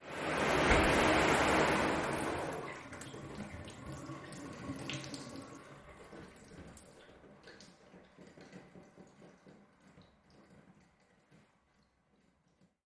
showerMuffledEnd.ogg